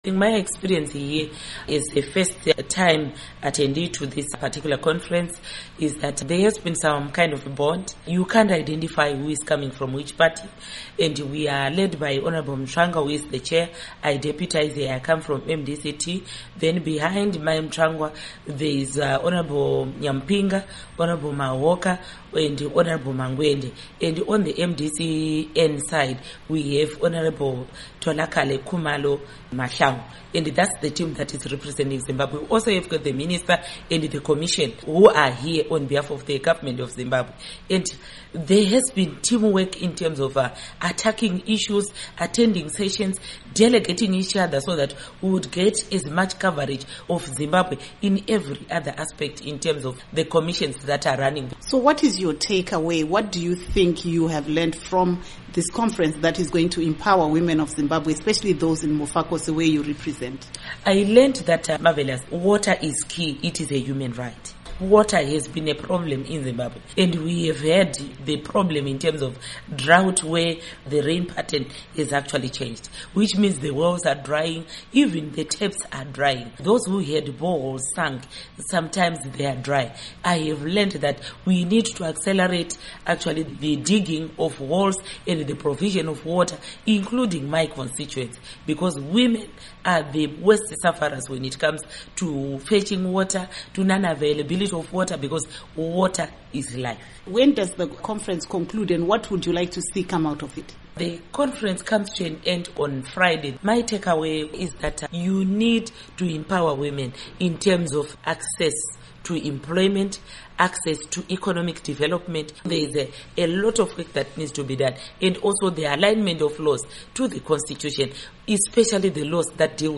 Interview WIth Honourable Paurina Mpariwa Gwanyanya